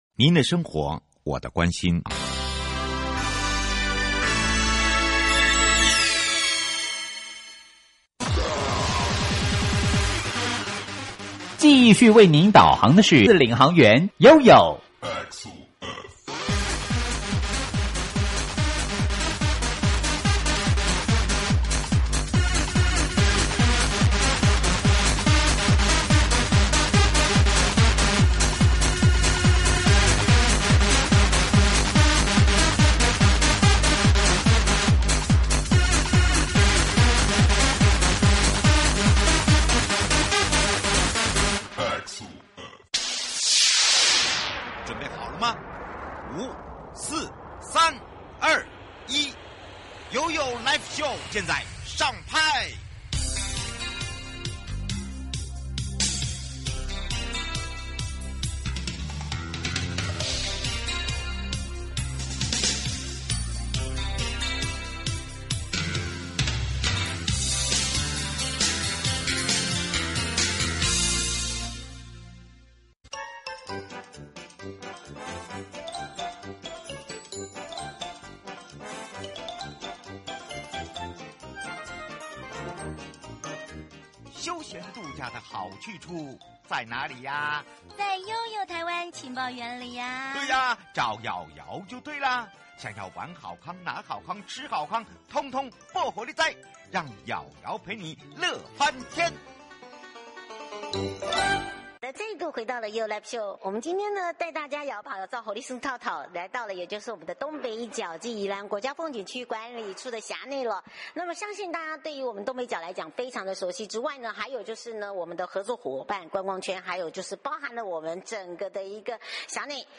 受訪者： 1. 東北角管理處